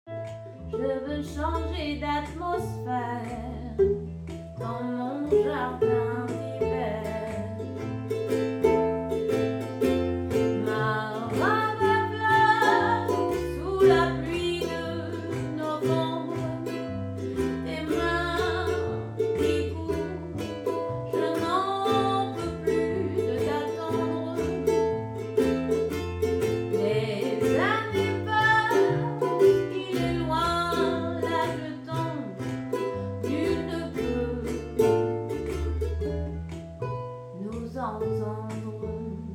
Ballades